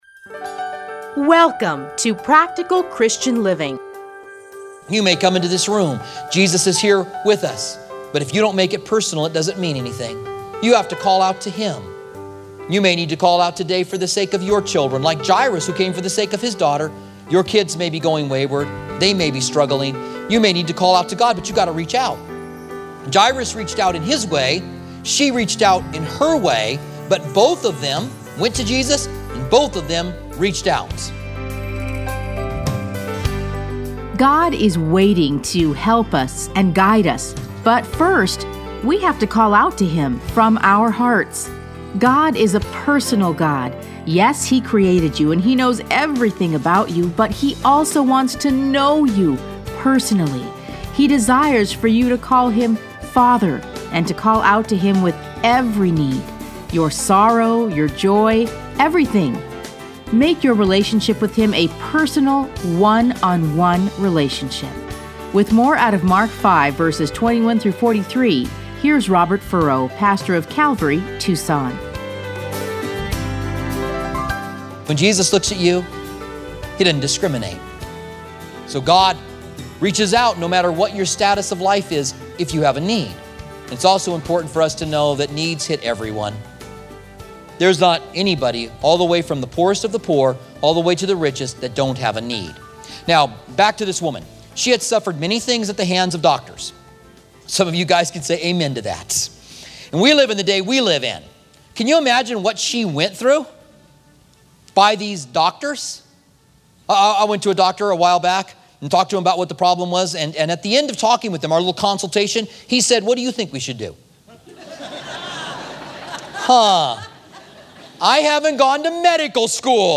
Listen to a teaching from Mark 5:21-43.